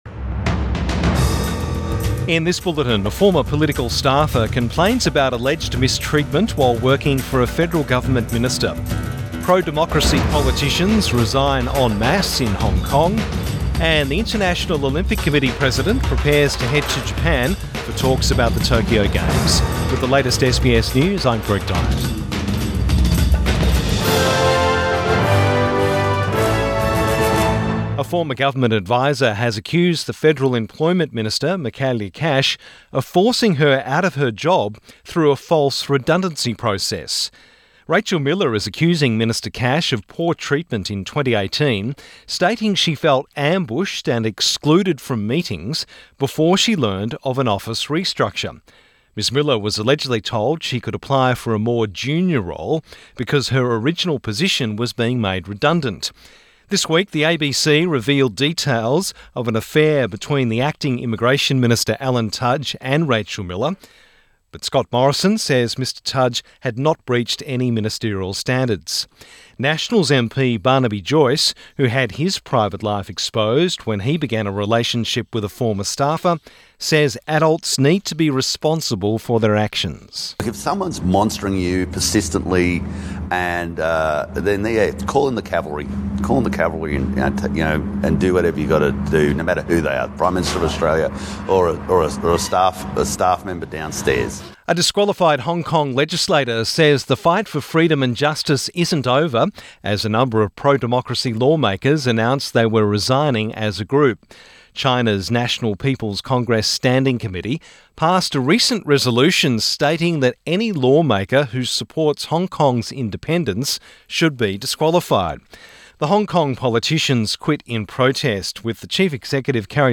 Midday bulletin 12 November 2020